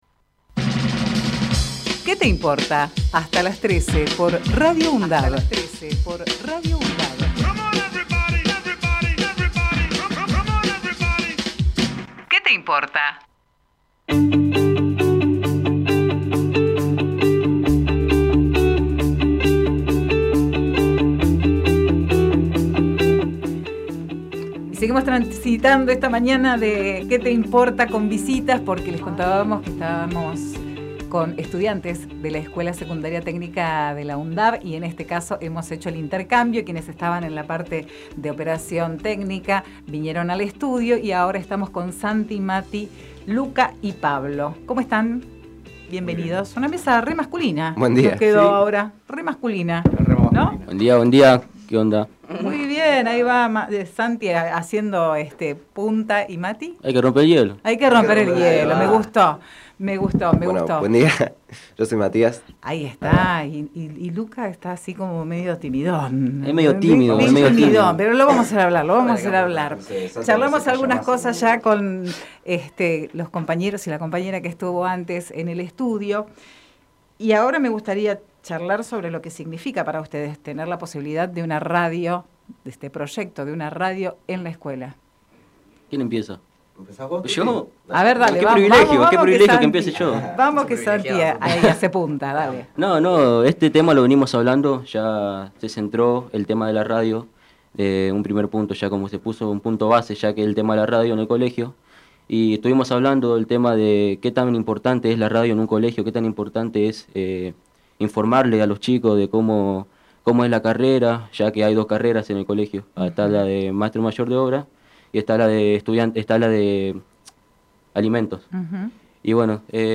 UNDAV - SEGUNDO GRUPO DE ESTUDIANTES Texto de la nota: Compartimos entrevista realizada en "Qué te Importa" a estudiantes de TSC." UNDAV ". Nos visitan en el estudio de la Radio y nos cuentan del proyecto de Radio en la escuela - grupo 2 Archivo de audio: QUÉ TE IMPORTA - TSC.